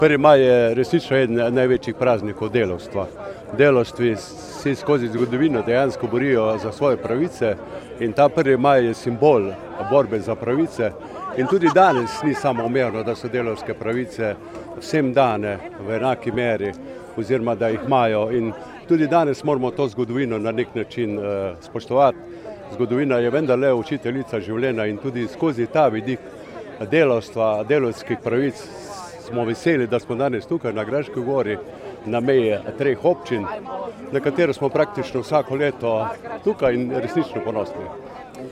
Veliko tradicionalno prvomajsko srečanje je potekalo tudi na tromeji občin Slovenj Gradec, Mislinja in Graška gora.
O pomenu praznika pa podžupan Mestne občine Slovenj Gradec Peter Pungartnik: